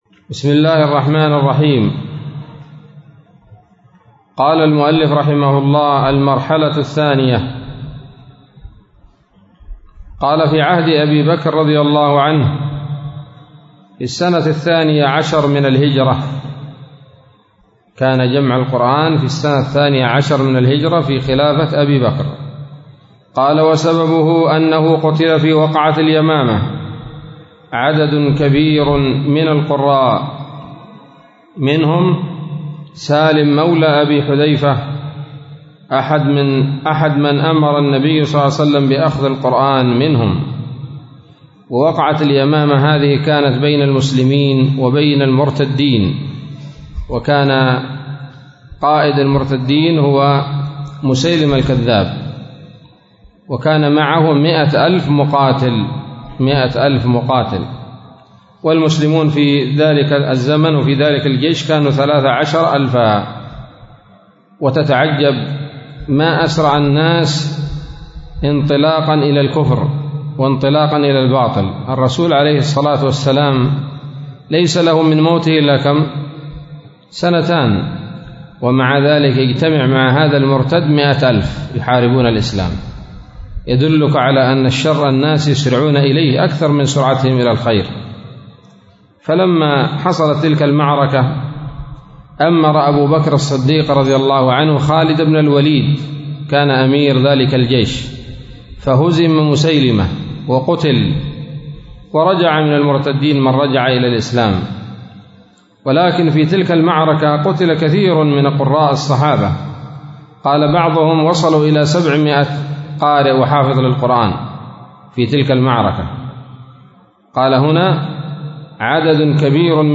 الدرس الخامس عشر من أصول في التفسير للعلامة العثيمين رحمه الله تعالى